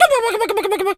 turkey_ostrich_gobble_12.wav